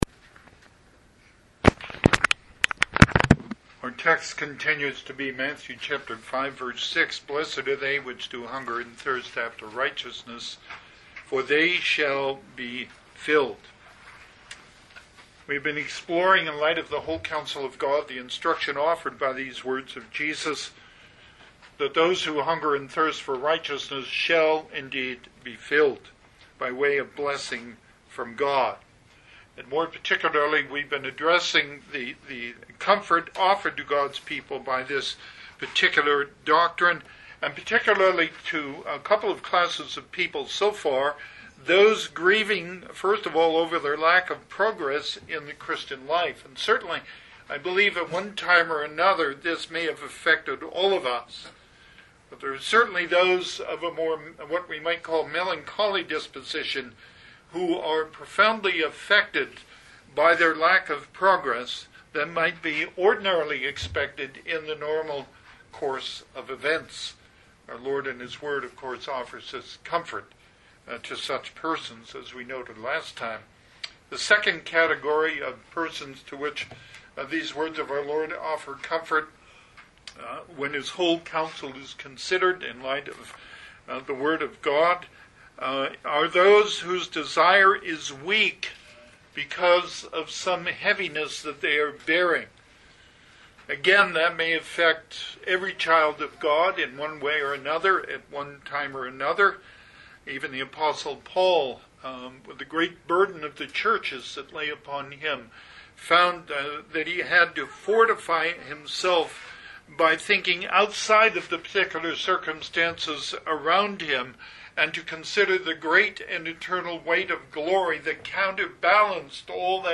Service Type: Sunday AM Topics: A Manifesto on Discipleship